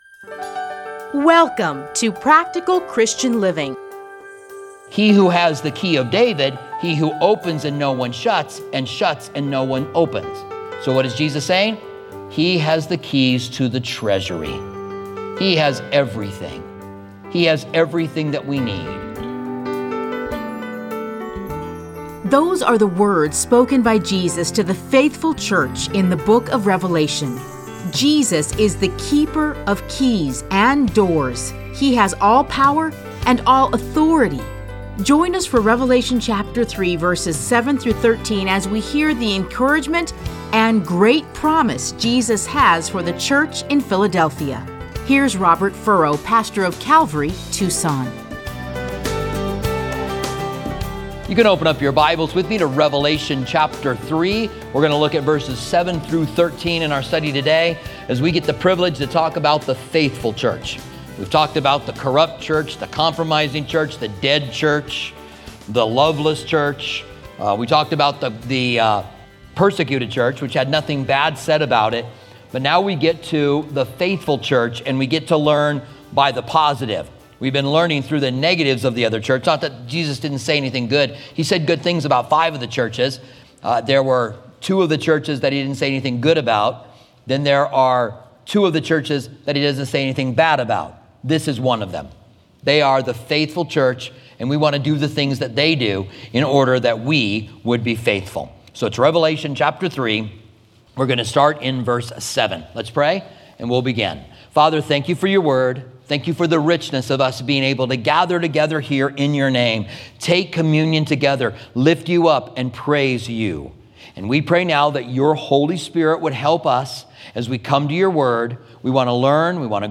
Listen to a teaching from Revelation 3:7-13.